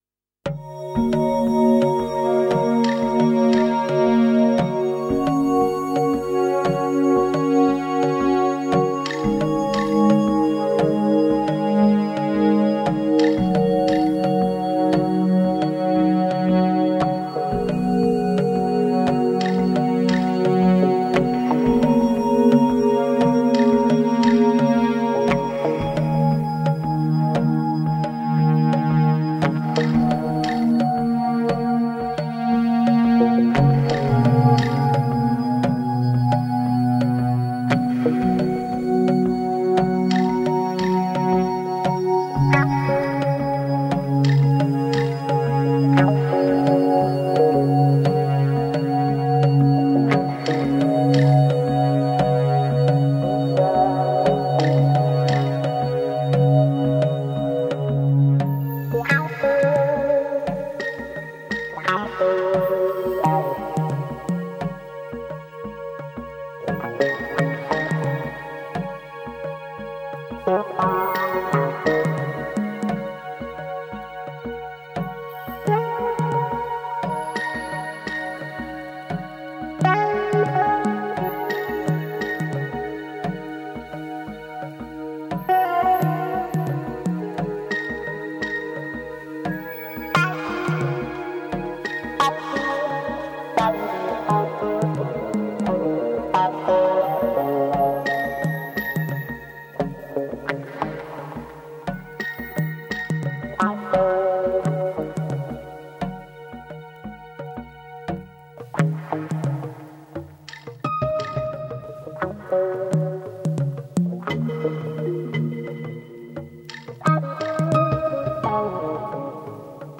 minimalistic lyrical content
sensuous melodies and hypnotic rhythm patterns